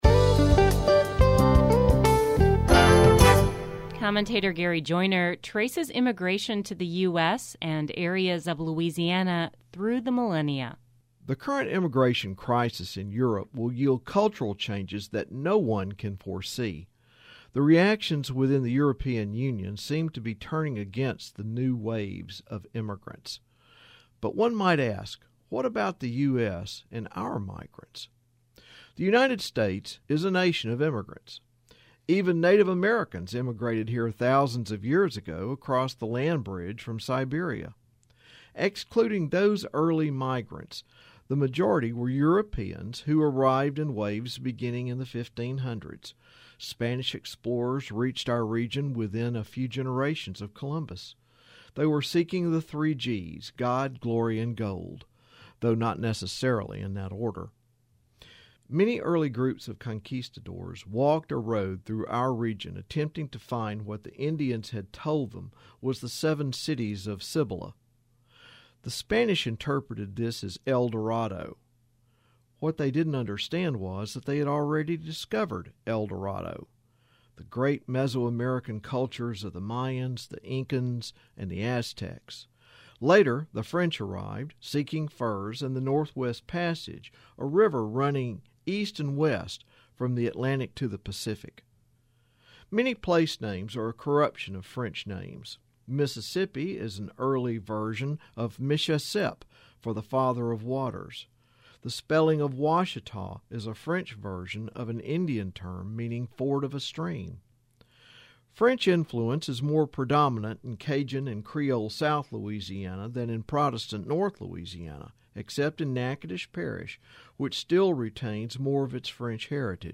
History Matters #446 - Immigration commentary